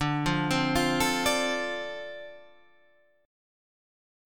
D Minor 7th